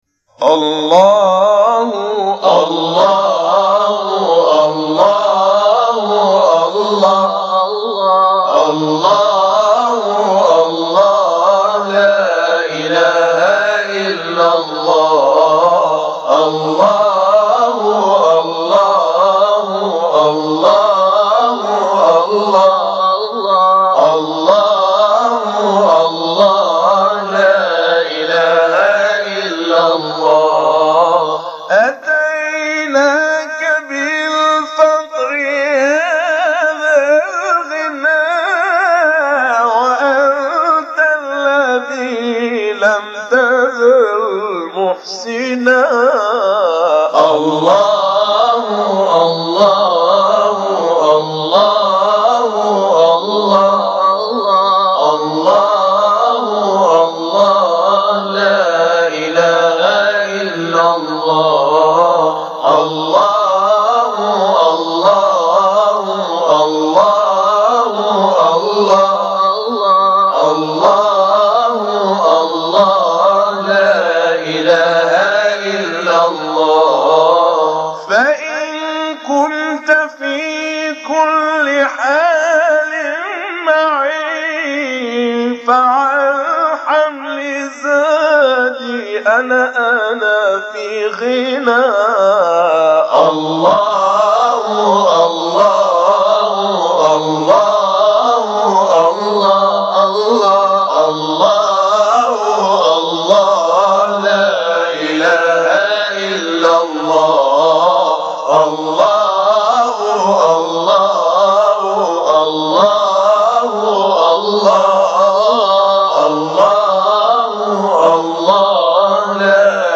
الله الله لا إله إلا الله - حجاز - لحفظ الملف في مجلد خاص اضغط بالزر الأيمن هنا ثم اختر (حفظ الهدف باسم - Save Target As) واختر المكان المناسب
allah-allah_Hijaz.mp3